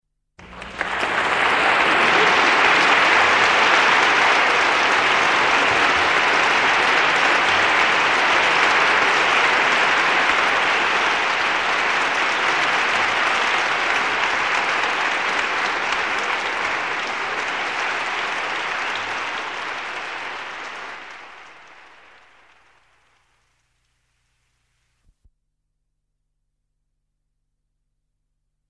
APLAUSOS EFECTOS ESPECIALES DE SONIDO APLAUSO
Ambient sound effects
aplausos_Efectos_Especiales_de_sonido_-_APLAUSO.mp3